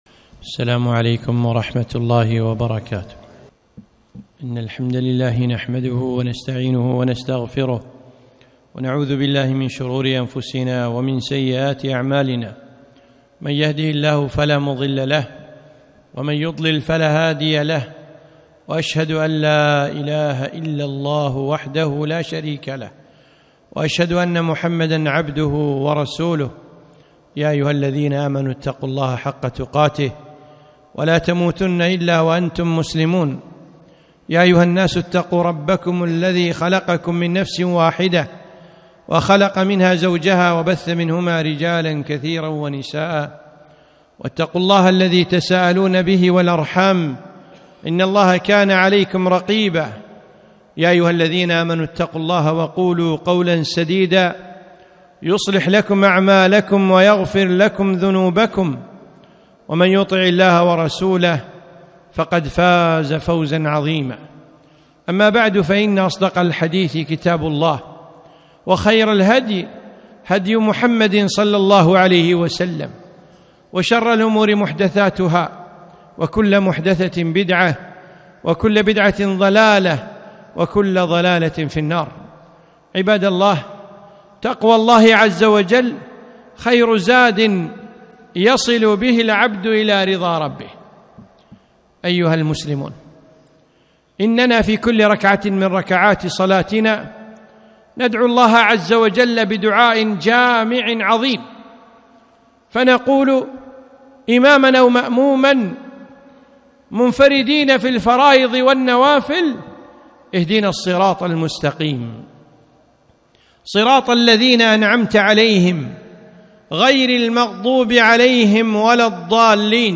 خطبة - صراطا مستقيما